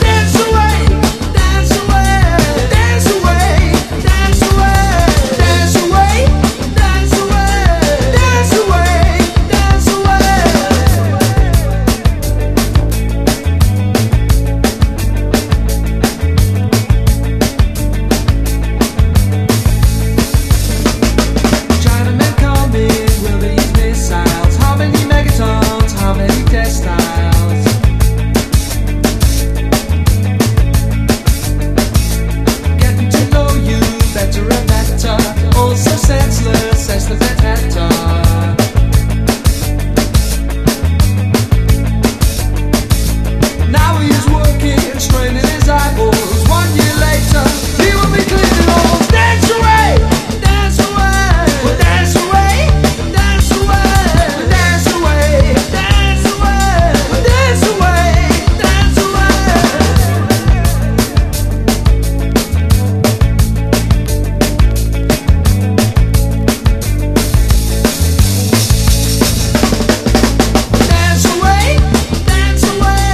ROCK / 90''S～ / 10'S / INDIE (UK)
ヤングでパンキッシュなひねくれインディー・トリオの限定盤！
カラカラと響き渡るギター・リフを絡ませながら弾けた